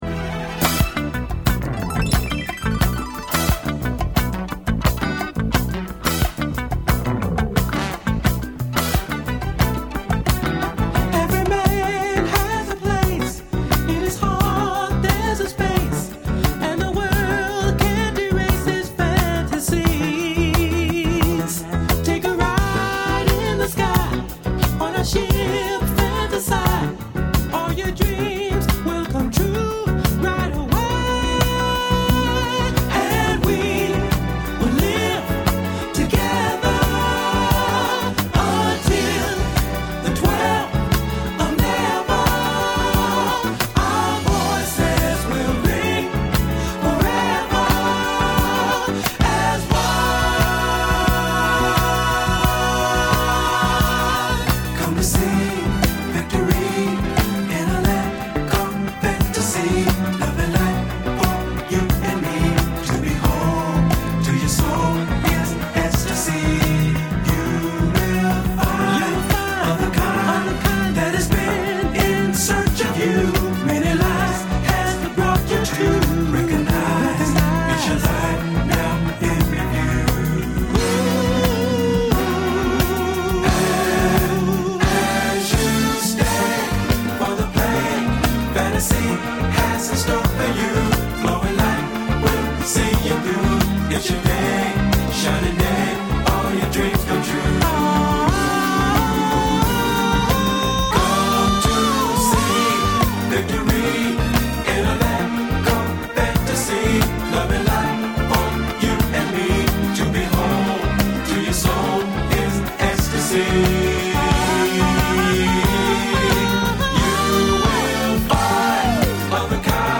con una sezione di fiati
Dance